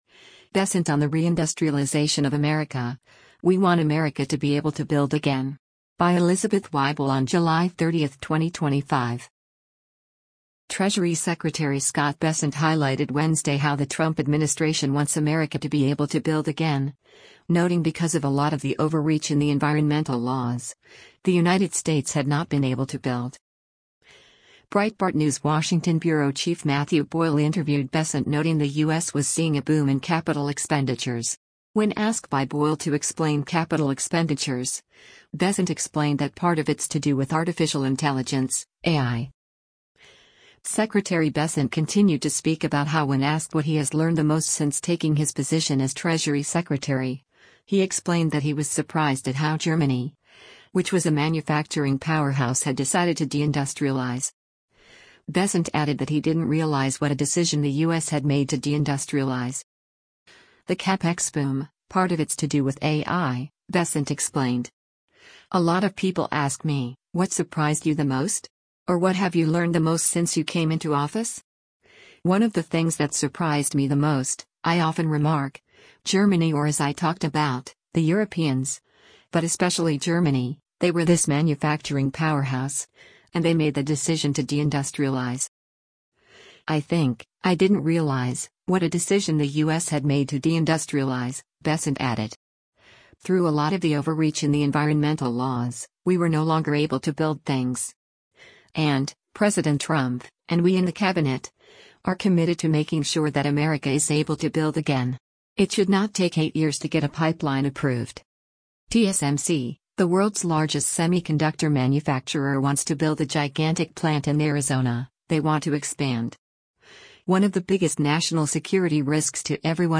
During the interview, Bessent also expressed that people had not “seen nothing yet.”